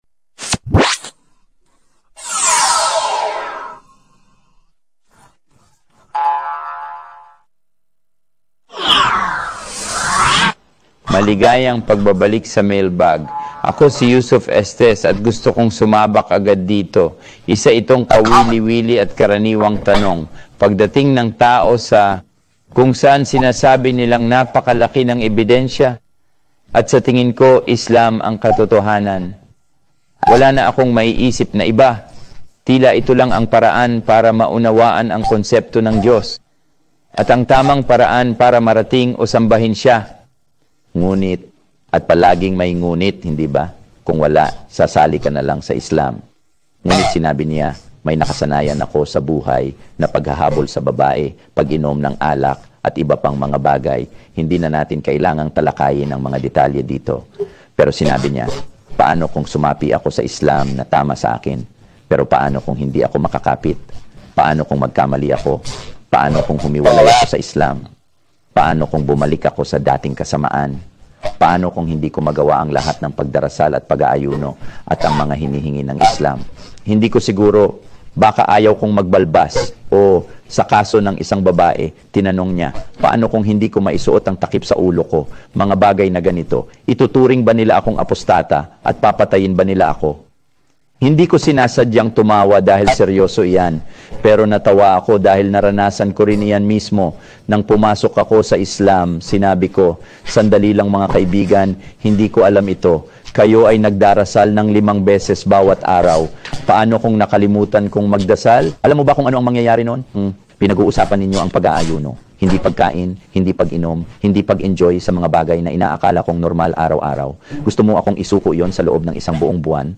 lekturang ito